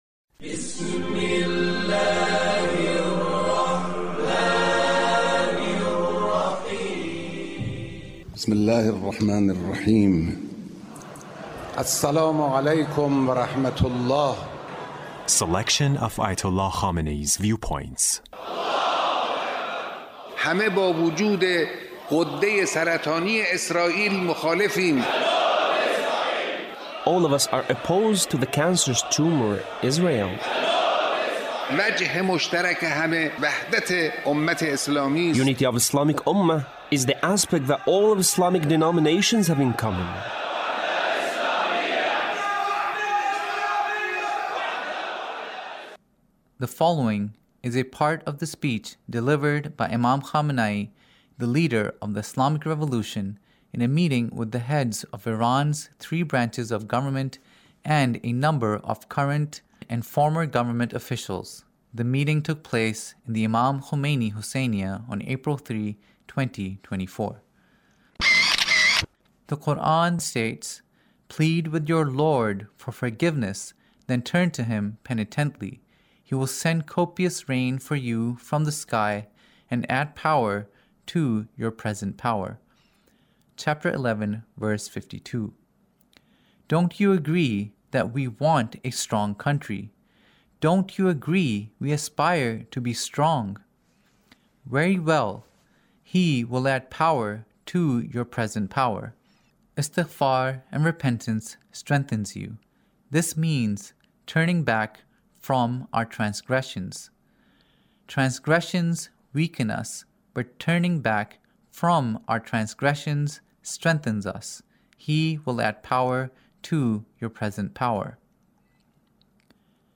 Leader's Speech in a Meeting with the Three Branches of Government Repentance